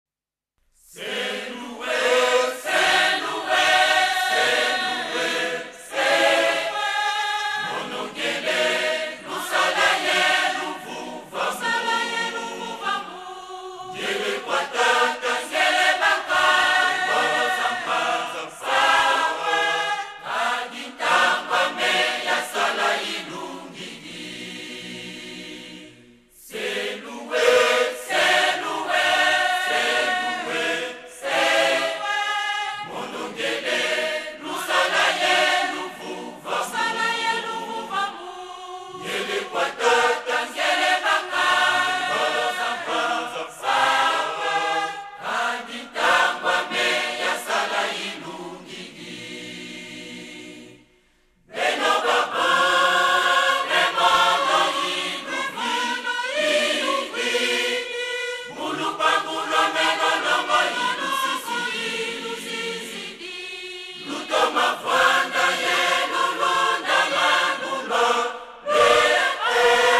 Liste des cantiques